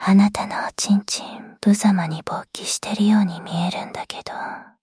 生成される音声の品質は悪くなさそうだけど喘ぎ声とかはまだうまく出せてない。
一応囁くとかのタグ効かせられる